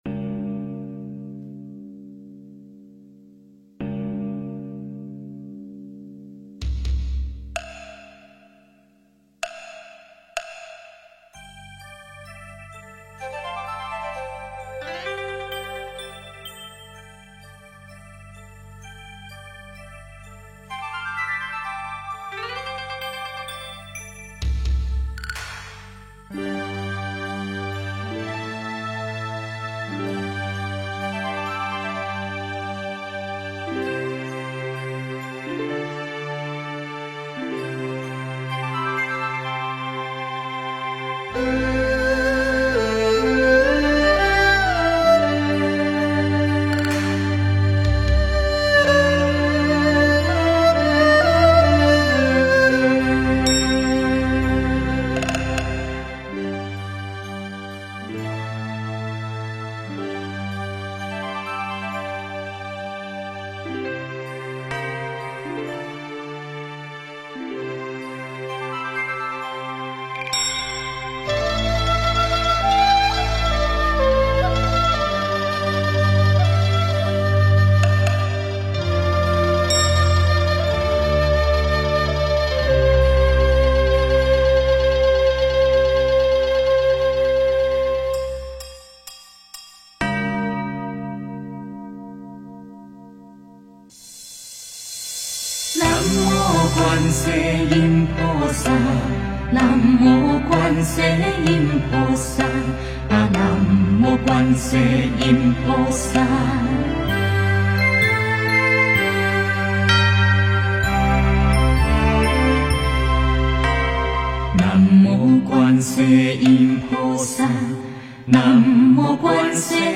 佛音 诵经 佛教音乐 返回列表 上一篇： 观世音菩萨圣号 下一篇： 消业障六道金刚咒(国语演唱版